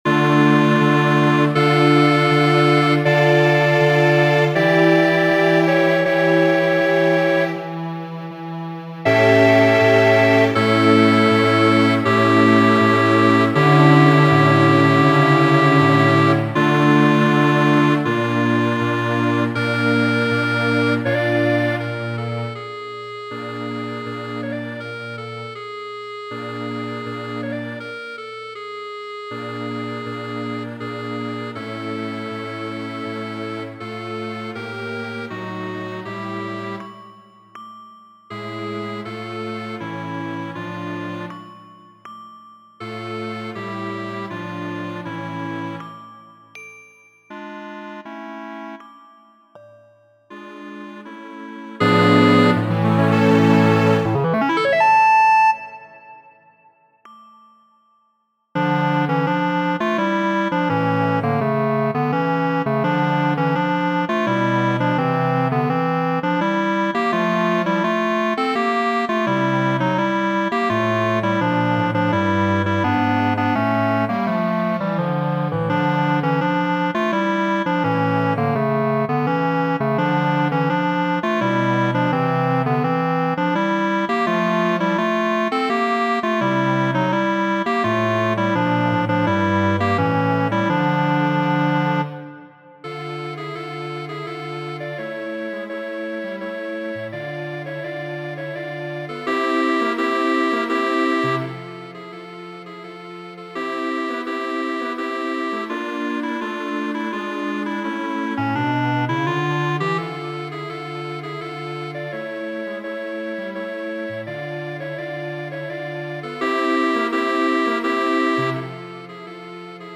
Variaĵoj pri popola temo Folioj de Hispanio, komponitaj de franca François de Fossa en Meksiko la 19-an jarcenton.